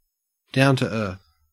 Ääntäminen
IPA : /ˌdaʊn.ɾəˈɚθ/